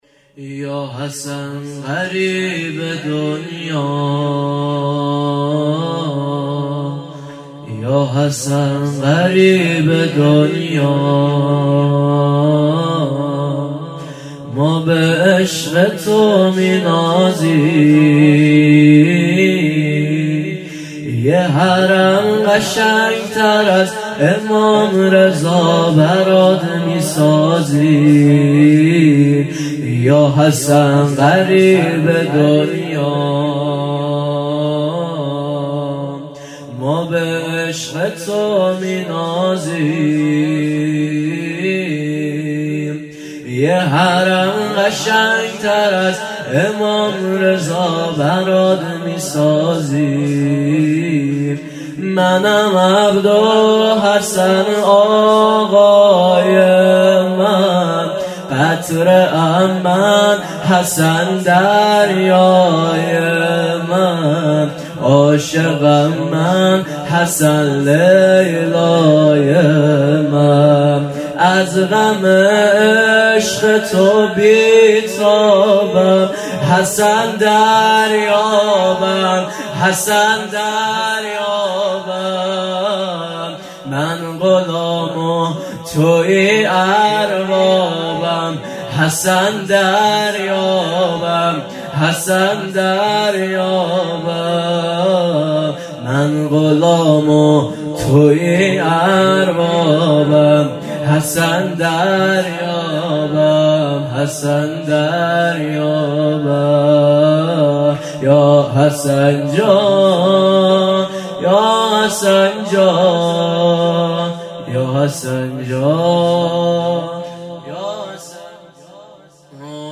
مسجد امام موسی بن جعفر علیه السلام
شهادت امام حسن مجتبی علیه السلام97